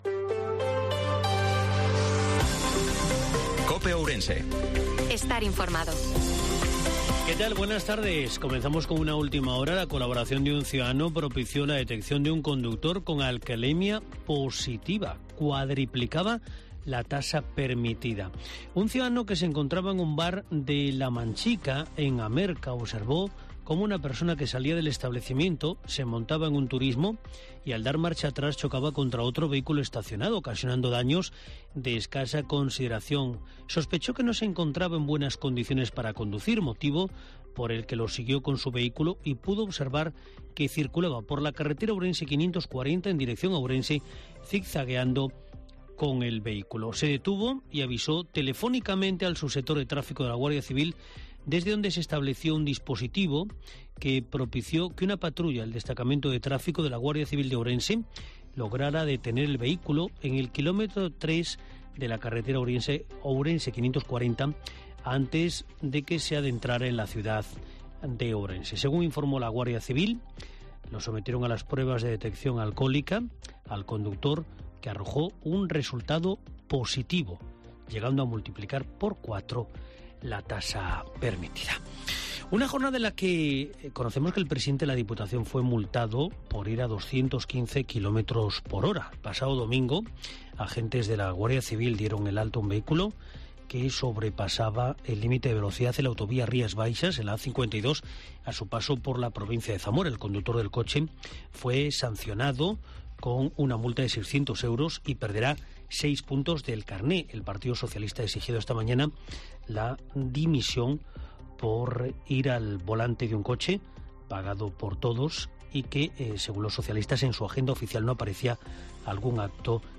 INFORMATIVO MEDIODIA COPE OURENSE-26/04/2023